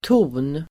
Uttal: [to:n]